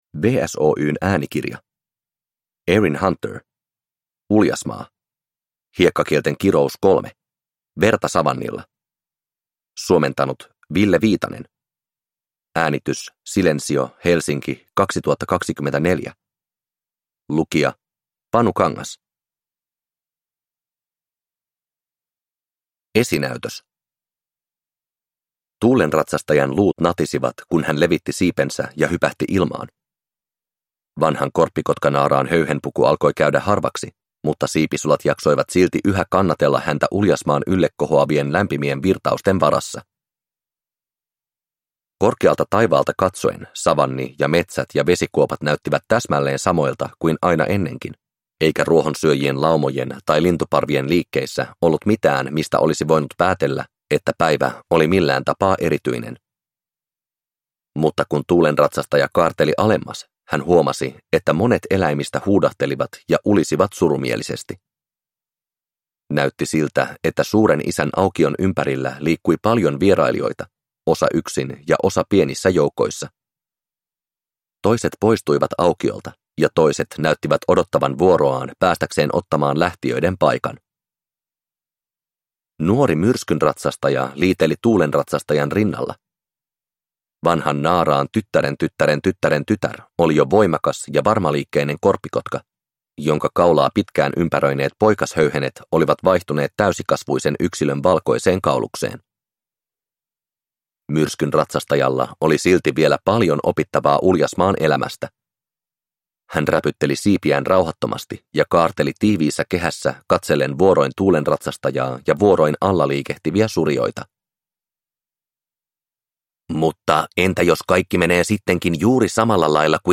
Uljasmaa: Hiekkakielten kirous 3: Verta savannilla – Ljudbok